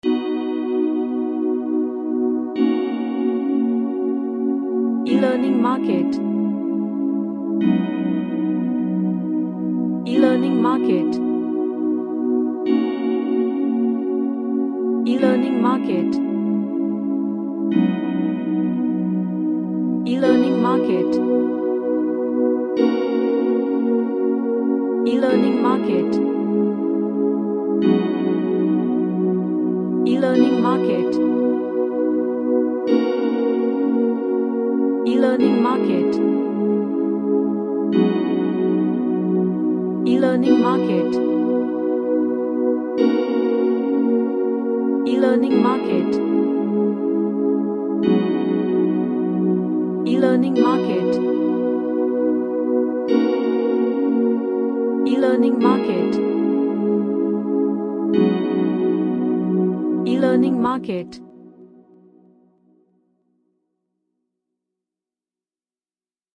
An Ambient track fraturing bell pads.
Relaxation / Meditation